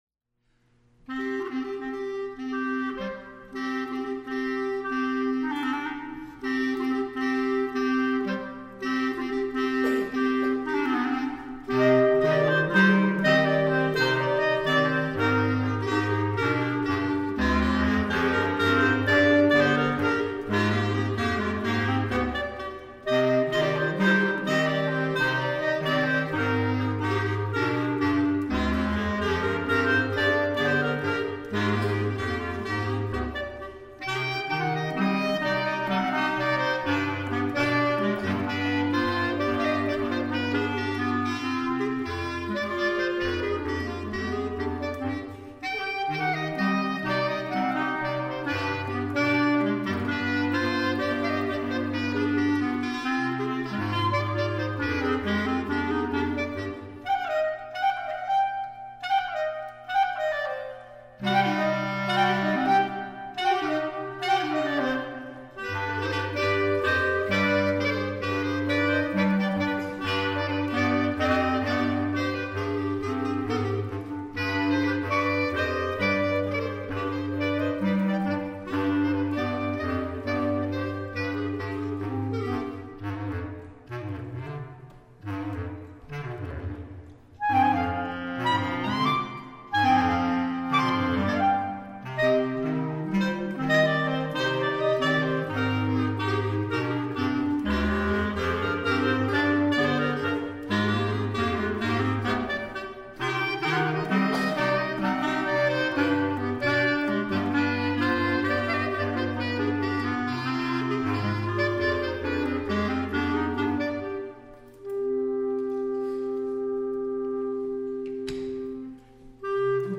Winter Concert January 31, 2010
Clarinet Ensemble
clarinet.mp3